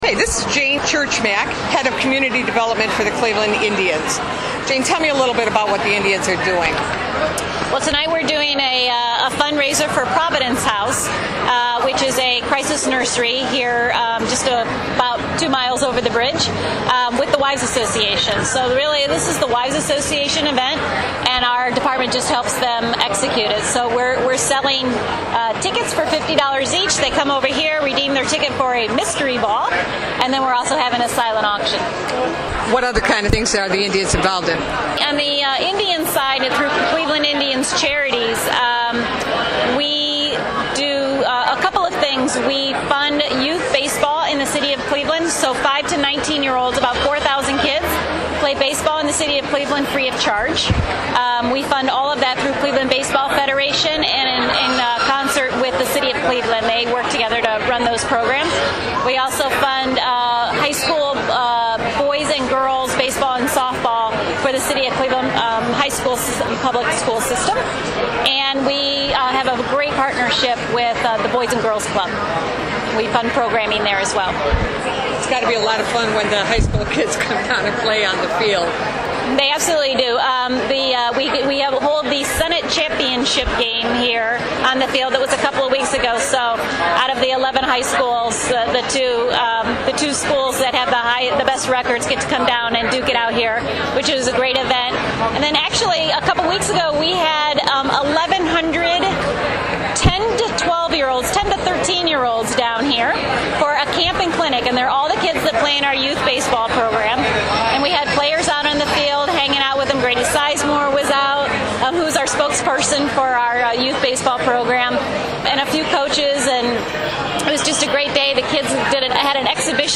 The Cleveland Indians Wives Association hosted its fourth annual Mystery Ball Fundraiser, presented by Dick's Sporting Goods, at Progressive Field on Friday, June 27, before the Tribe's game against the Reds.